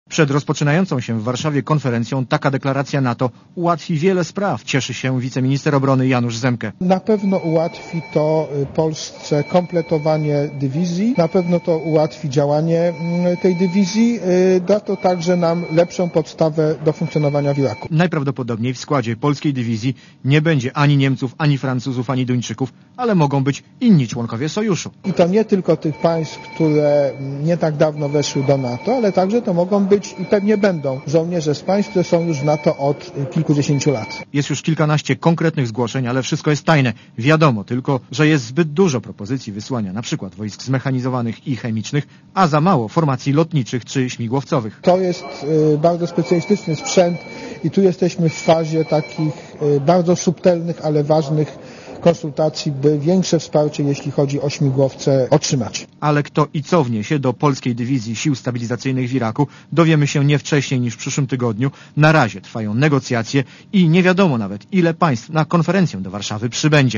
Niektóre państwa NATO być może wyślą swoich żołnierzy do Iraku pod polskim sztandarem - mówi Radiu Zet wiceminister obrony.